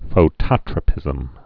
(fō-tŏtrə-pĭzəm, fōtō-trō-)